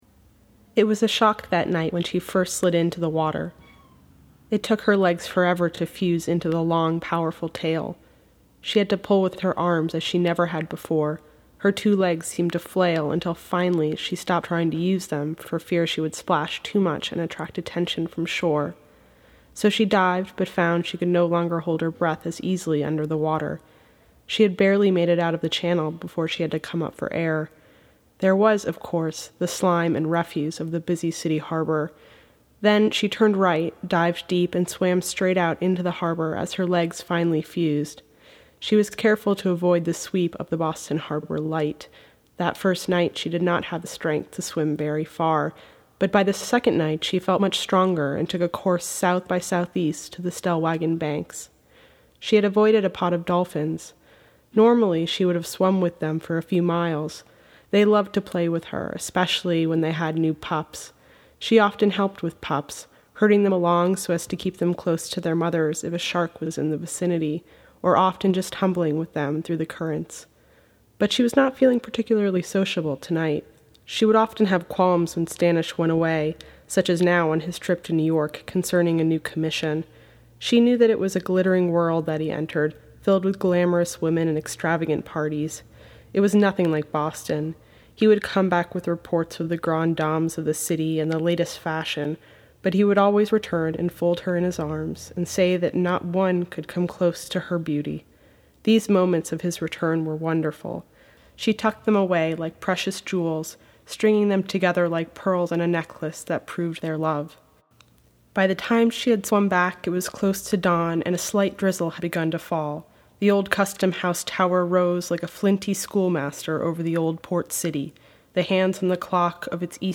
Here’s an audio excerpt from Daughters of the Sea: The Crossing
Reading of Daughters of the Sea: The Crossing An html5-capable browser is required to play this audio.